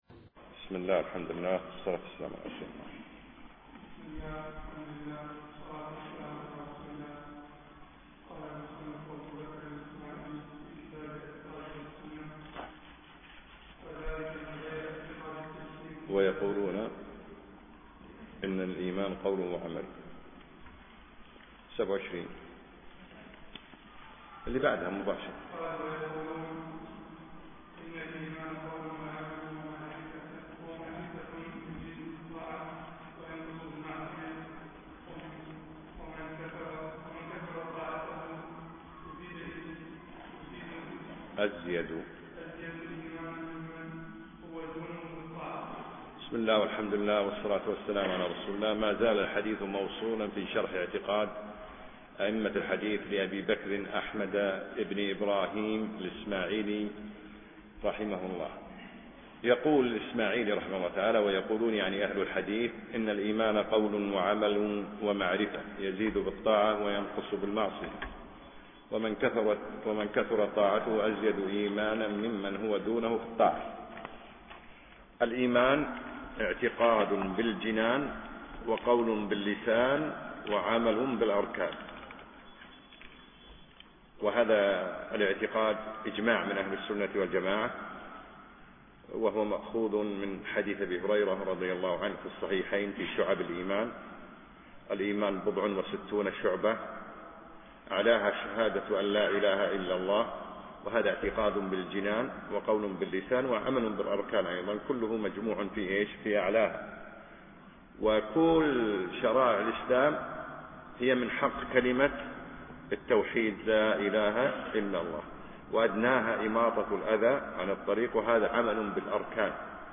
أقيمت الدورة في دولة الإمارات
الدرس الرابع